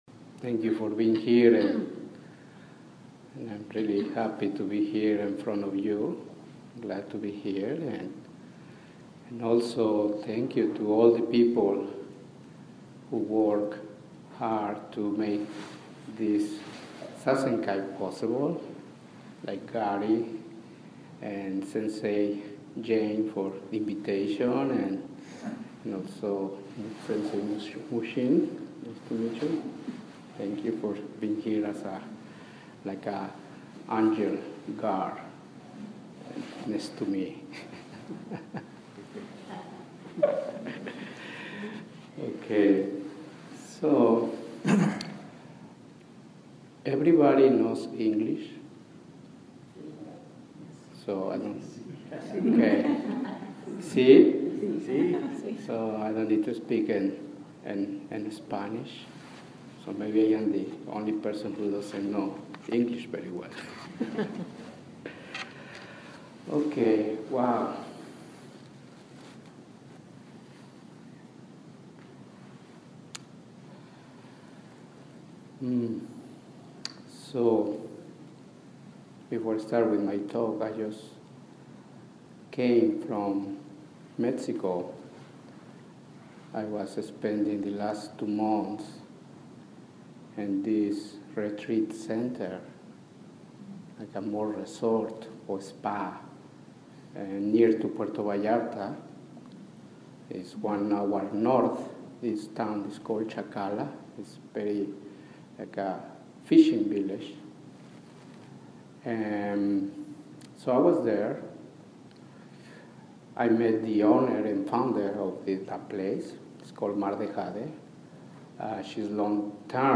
Dharma talk given at SPZG in May 2015